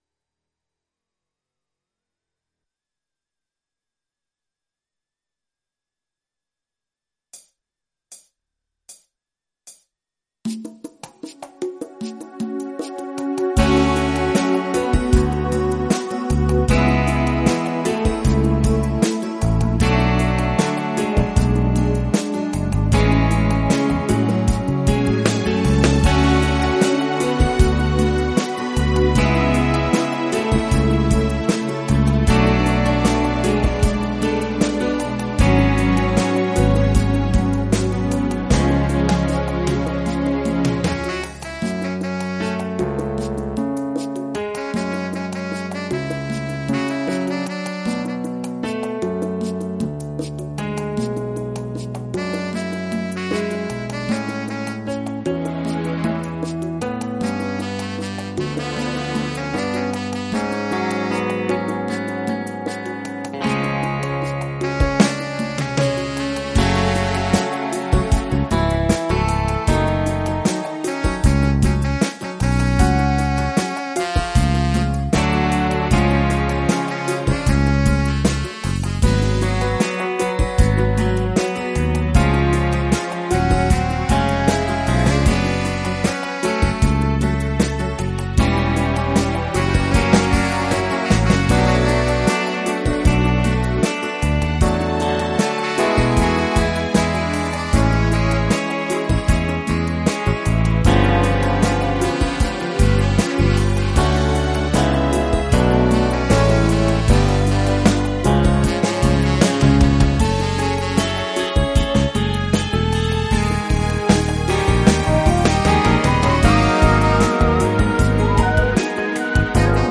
versione strumentale multitraccia